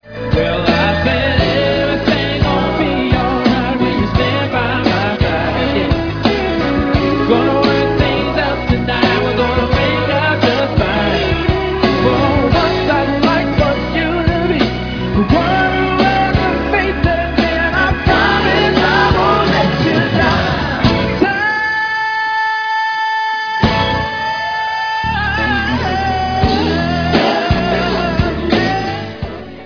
lead vocals and acoustic guitar